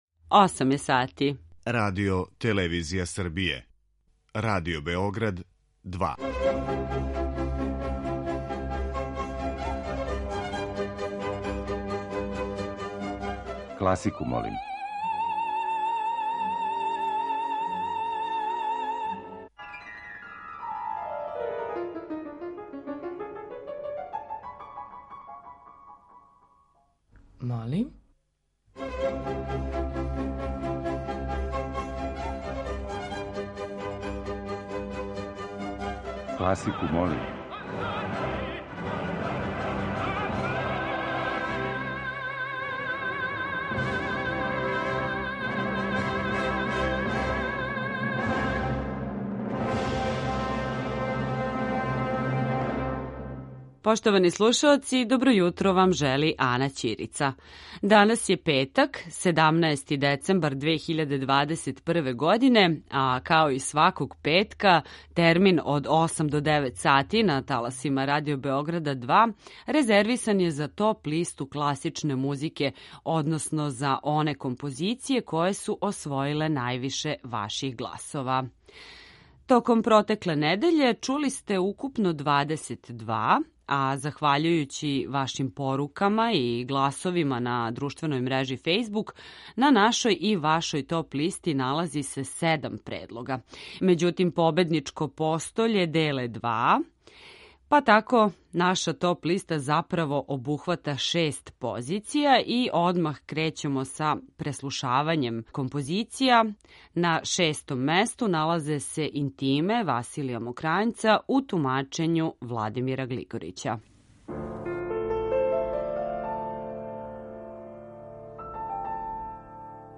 Топ-листа класичне музике
Подсетићемо се остварења која у наслову носе реч „ветар" или тонски сликају дување ветра.